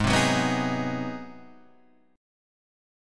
G#7#9 chord {4 3 4 4 4 4} chord